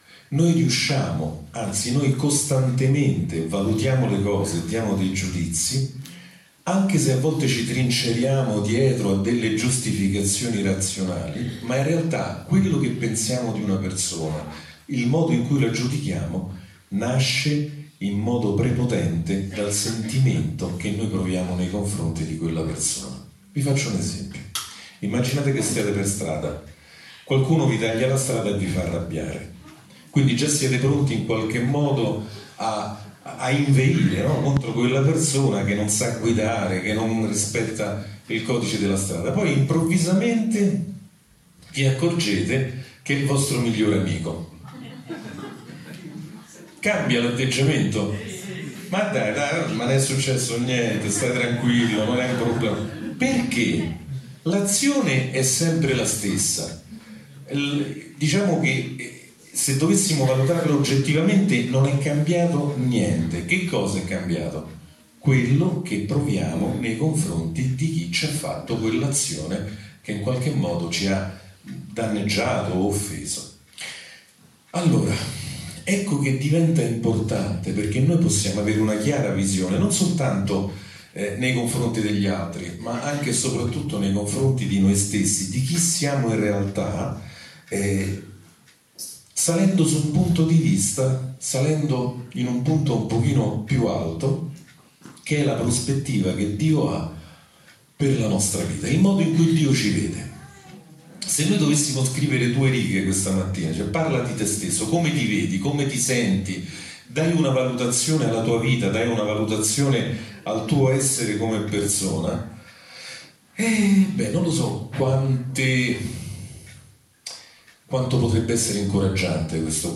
Sermoni della domenica